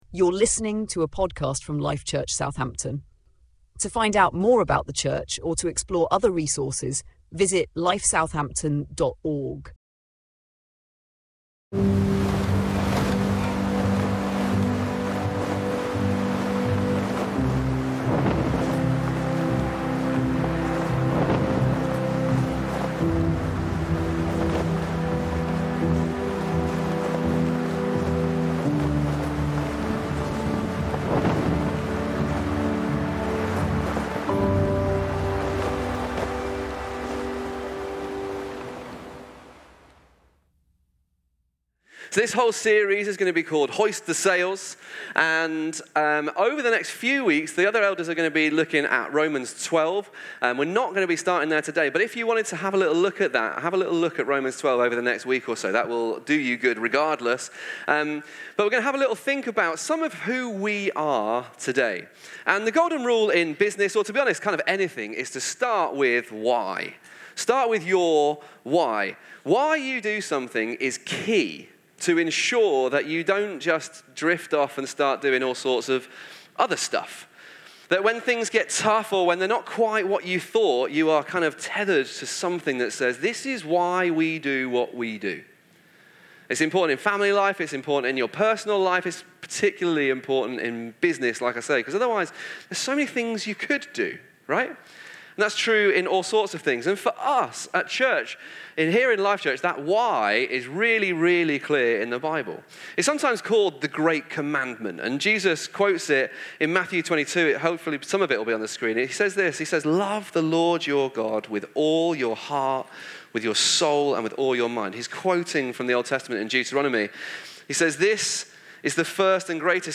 # Sermons